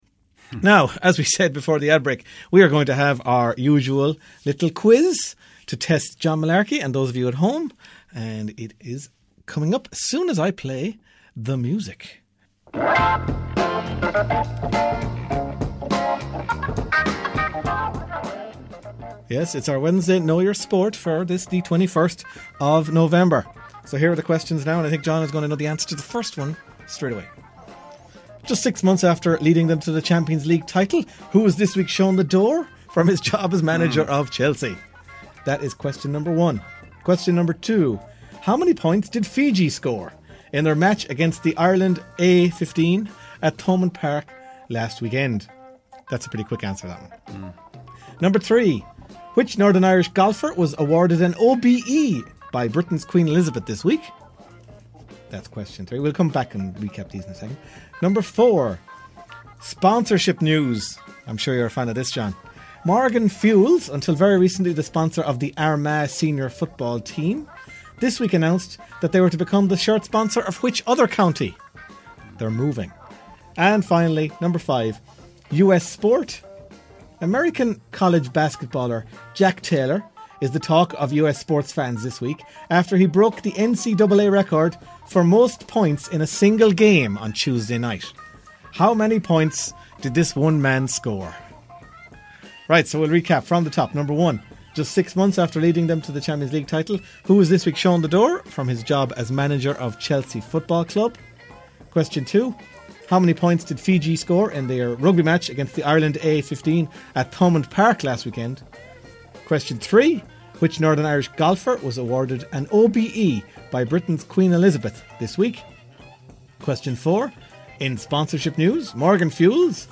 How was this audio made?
Five sports trivia questions from the Half-time Team Talk show on Claremorris Community Radio.